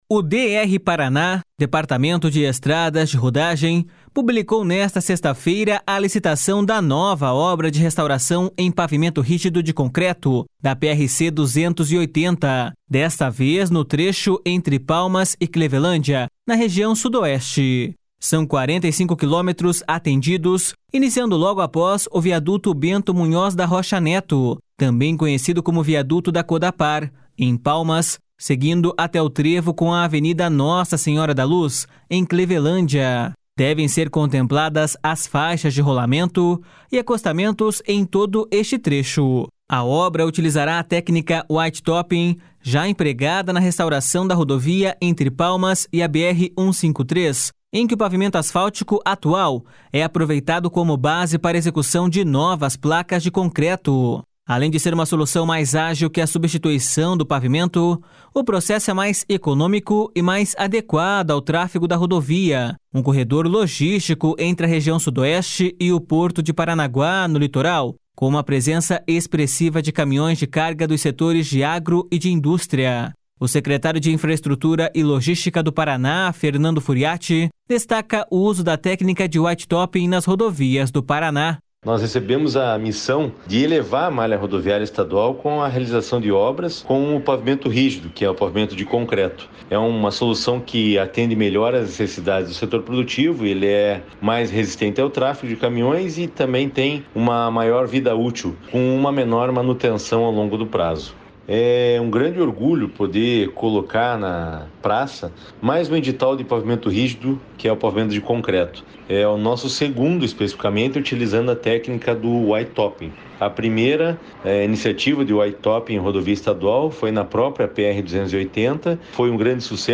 O secretário de Infraestrutura e Logística do Paraná, Fernando Furiatti, destaca o uso da técnica de whitetopping nas rodovias do Paraná.// SONORA FERNANDO FURIATTI.//
O pavimento rígido de concreto, destaca o diretor-geral do DER/PR, Alexandre Castro Fernandes, é empregado em vários países, sendo uma solução adotada pelo órgão para atender rodovias estaduais com grande volume de transporte de carga.// SONORA ALEXANDRE CASTRO FERNANDES.//